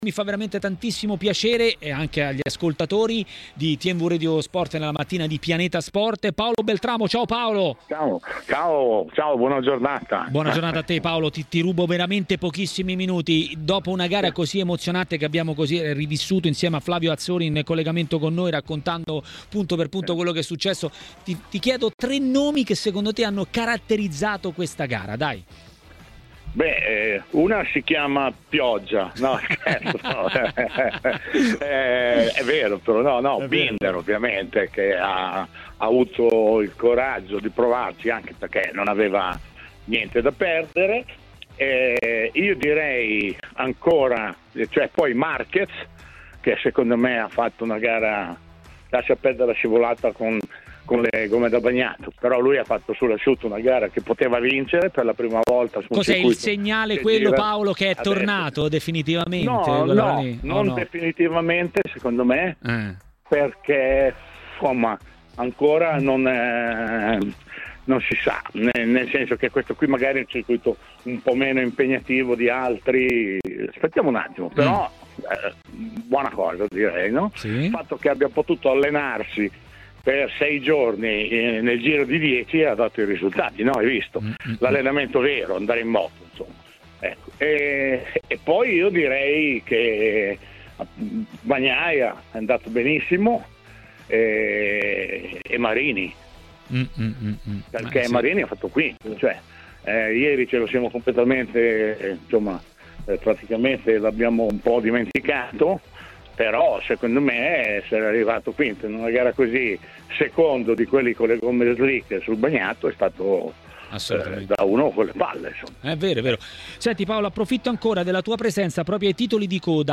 MotoGP